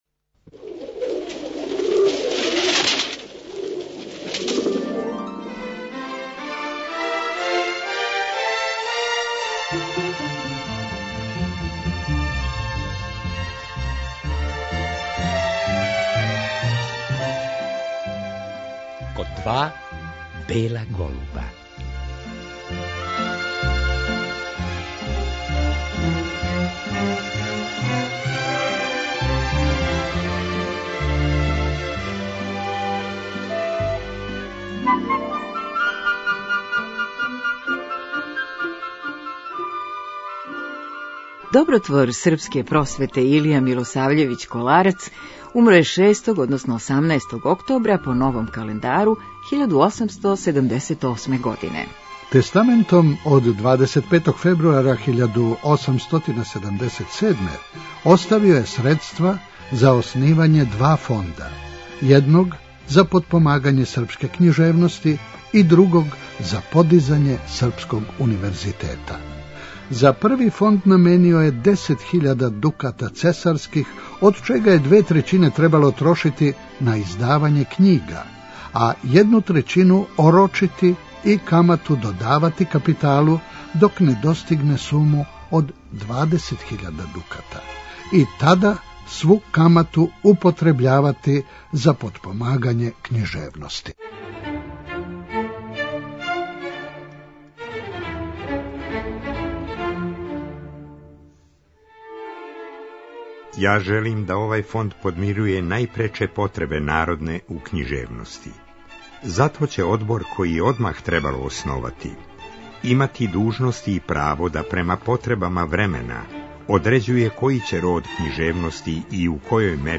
Неке делове из те књиге прочитаћемо у емисији.